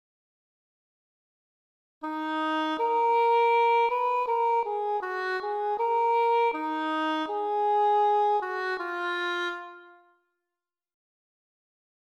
Fugue subject:
Fugue d# subject
fugue-d-subject.mp3